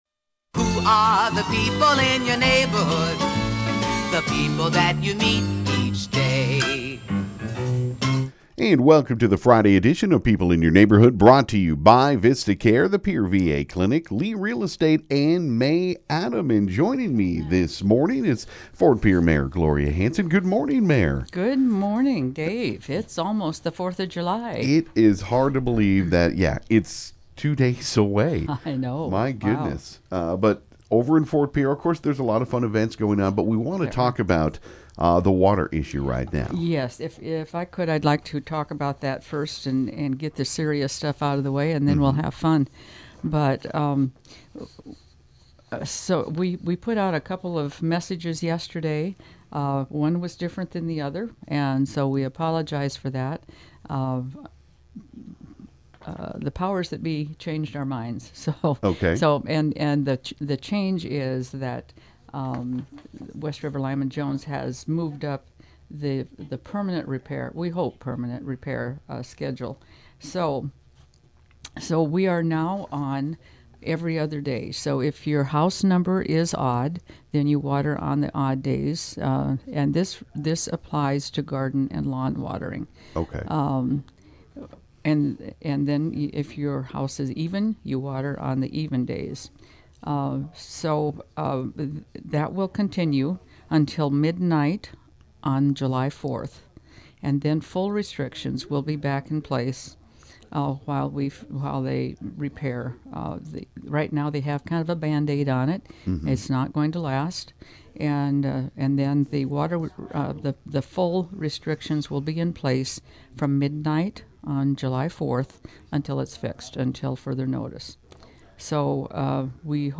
Then the 4th of July Parade committee talked about Sunday’s parade.